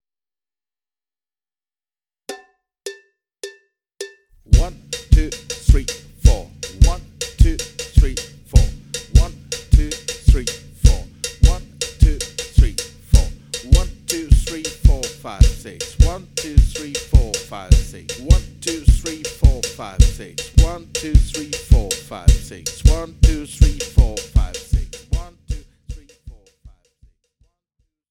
Besetzung: Schlagzeug
35 - Bembé-Pattern